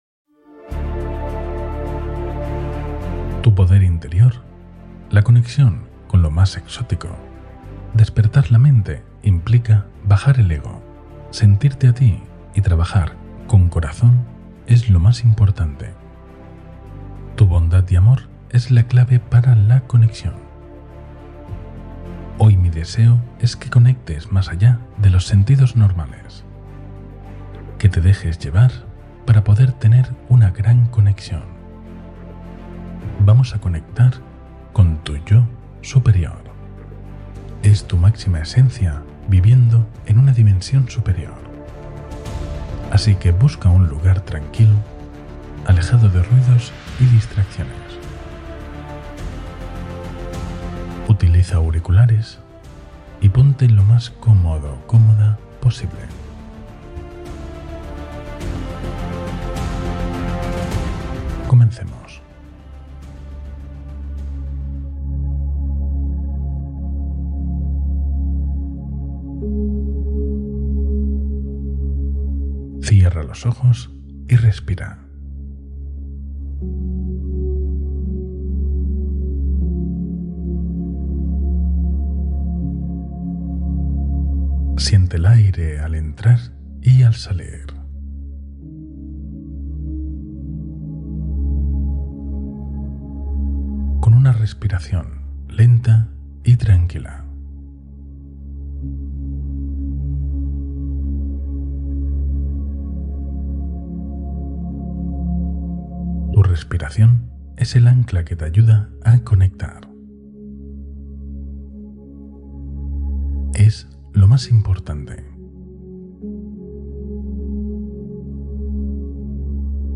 Conecta con tu yo superior y despierta tu potencial máximo con esta meditación guiada